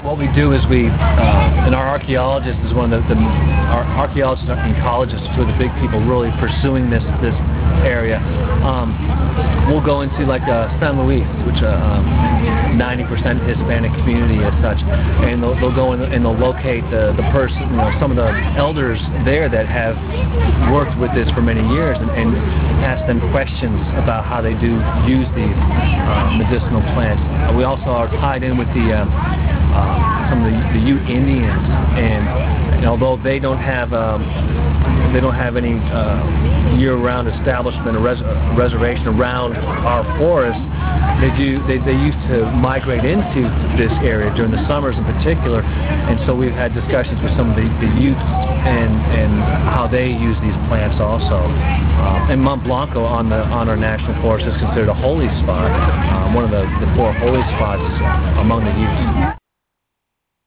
(Read full interview)